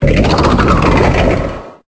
Cri de Sinistrail dans Pokémon Épée et Bouclier.